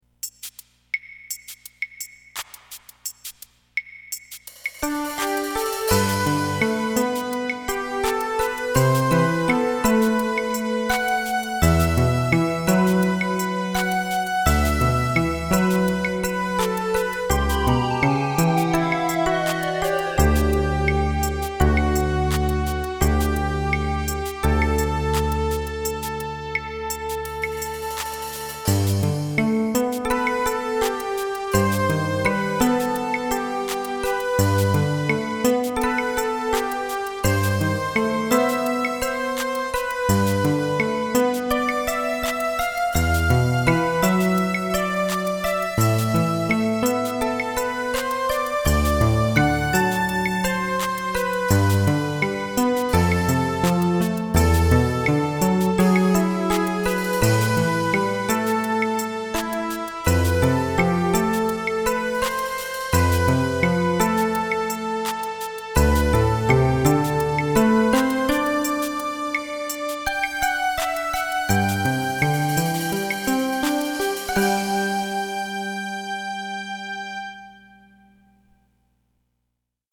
MT-32 MIDI conversion
As recorded from the original Roland MT-32 score